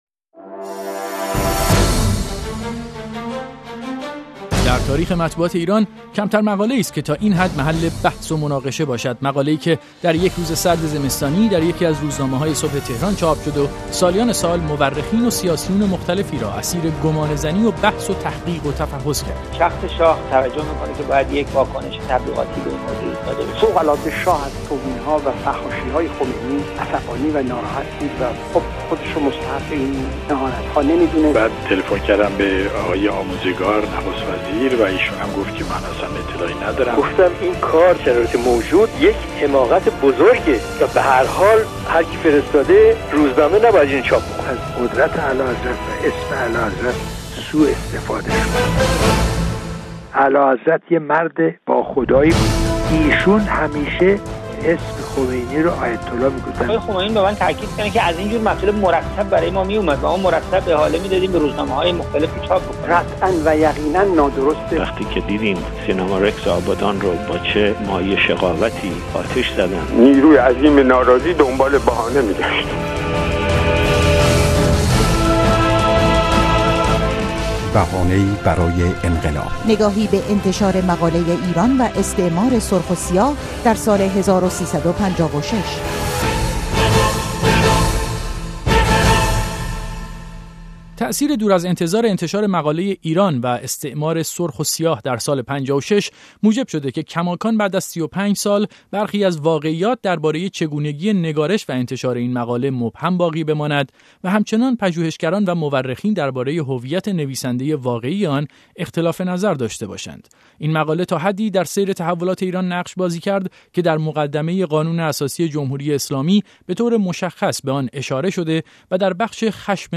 مستند رادیویی